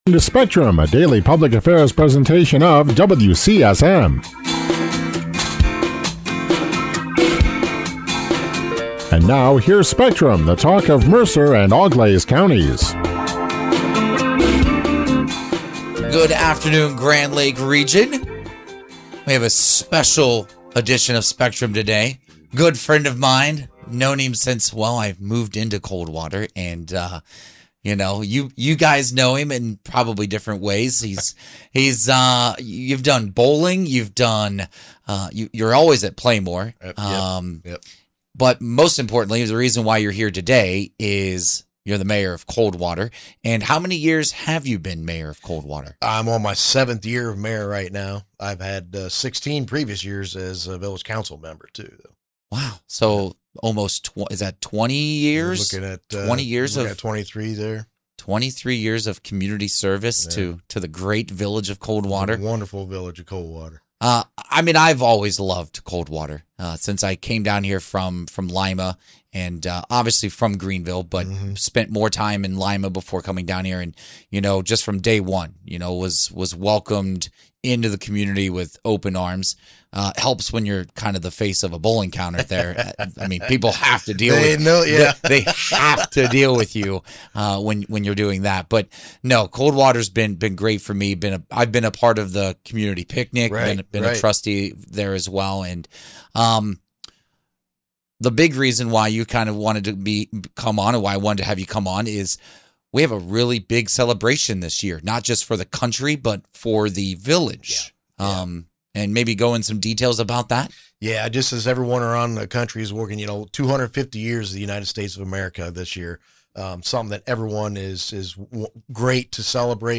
Listen to the entire interview ---> Click Here Coldwater Mayor Doug Bertke joined WCSM’s Spectrum for a special conversation focused on Coldwater’s plans tied to the nation’s 250th anniversary celebration. Bertke said the village is connecting the milestone to its annual Red, White, and Blue Celebration and adding a major historical piece: the unearthing of a time capsule buried 50 years ago.
SPECTRUM WITH COLDWATER MAYOR DOUG BERTKE.mp3